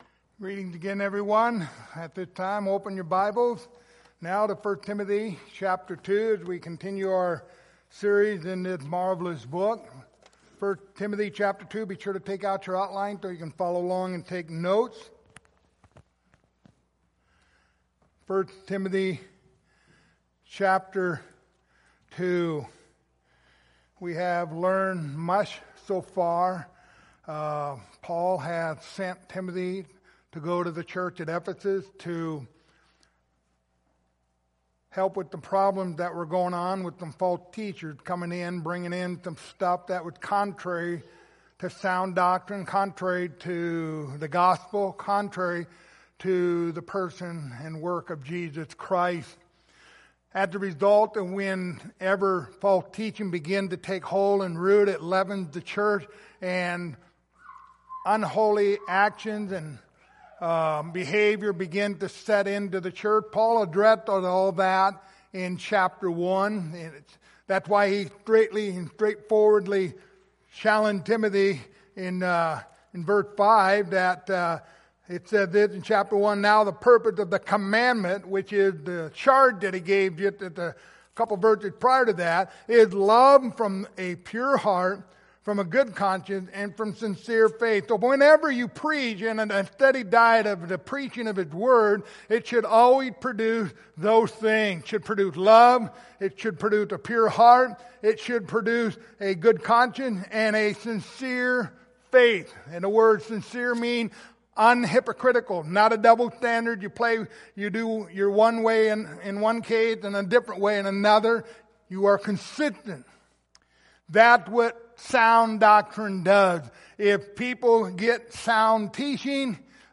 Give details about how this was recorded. Passage: 1 Timothy 2:9-15 Service Type: Sunday Morning